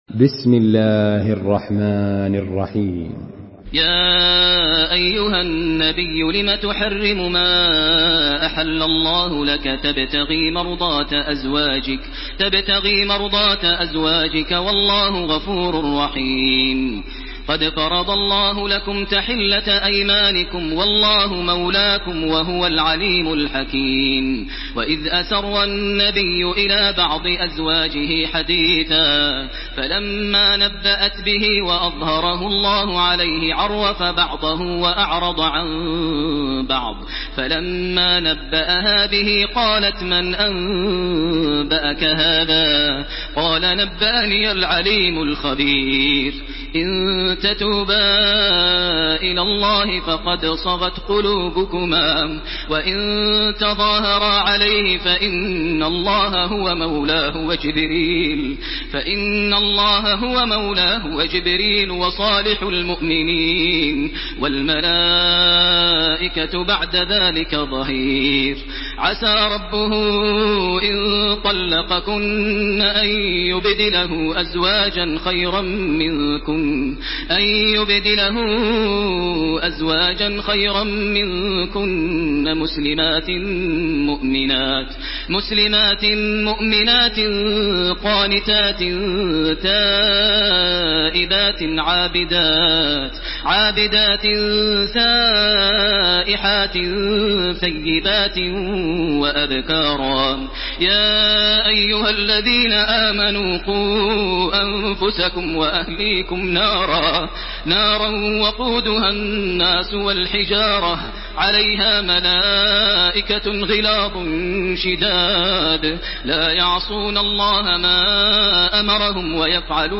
Surah At-Tahrim MP3 in the Voice of Makkah Taraweeh 1429 in Hafs Narration
Murattal